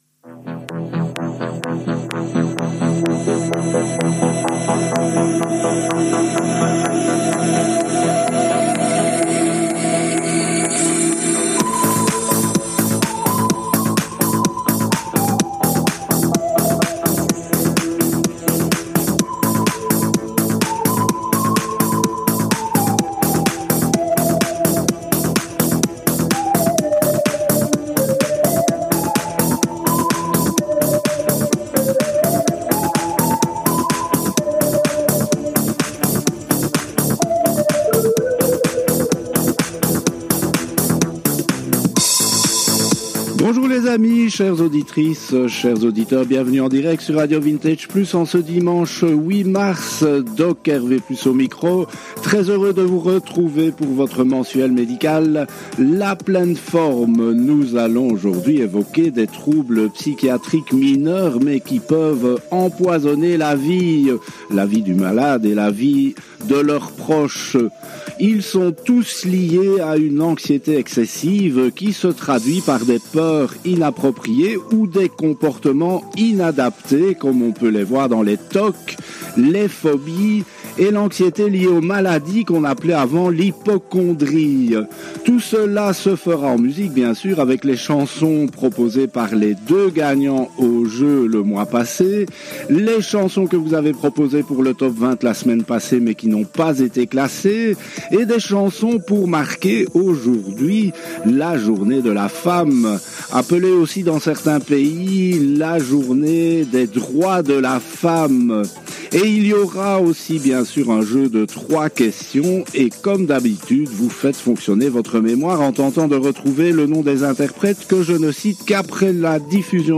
Emission mensuelle musicale et médicale sur un sujet particulier